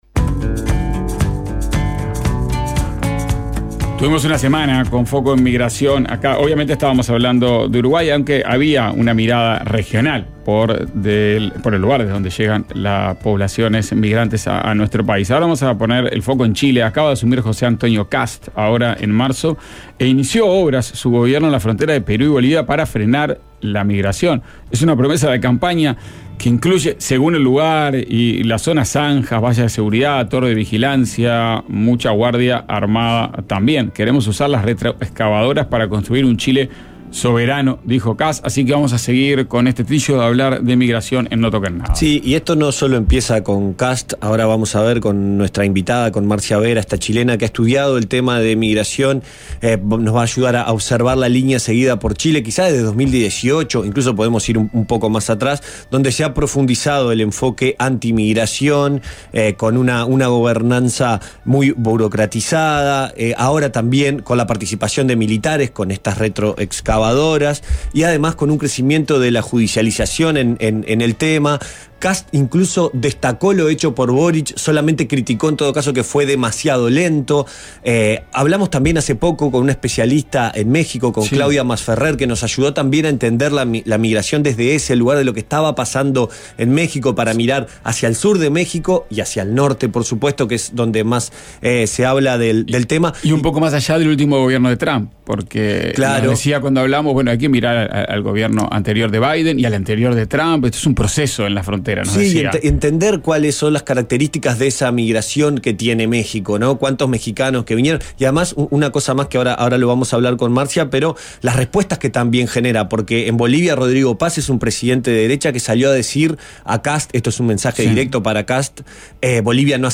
El actor y director uruguayo estuvo en No toquen nada. Habló de esas obras y de cómo es hacer cine hoy en el Río de la Plata.